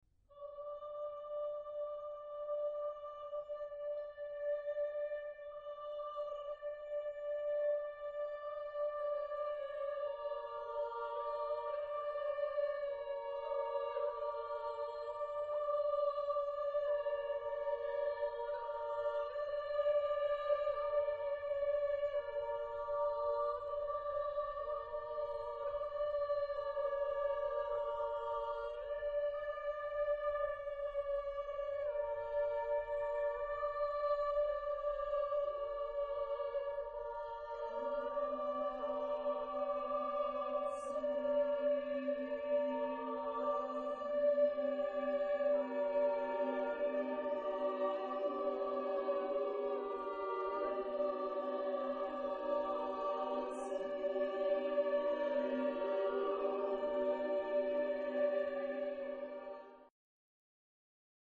Genre-Style-Forme : Sacré ; Motet
Type de choeur : SSATBB  (6 voix mixtes )